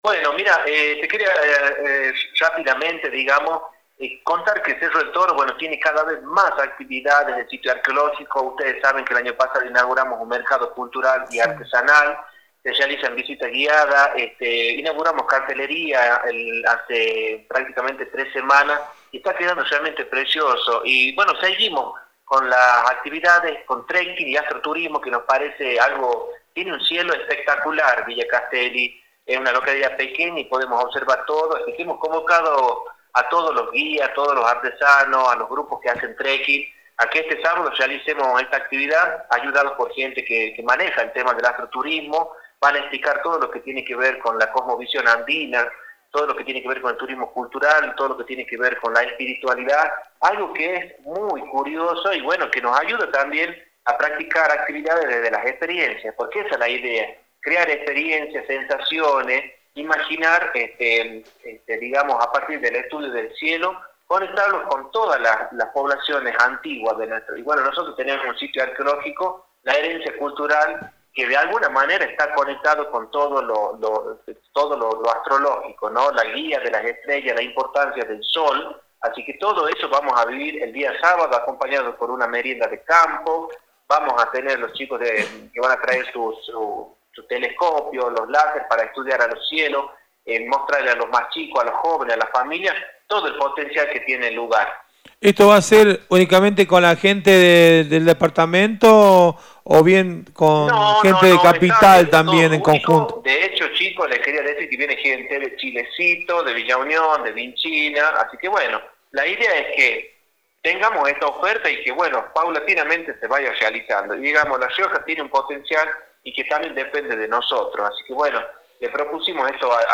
En el programa Nueva Época de Radio Libertad, el Secretario de Turismo, Julio Rojo, destacó el creciente interés por las propuestas de trekking y astroturismo en Villa Castelli, posicionando a la localidad como un destino emergente dentro de la región.